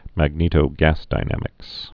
(măg-nētō-găsdī-nămĭks)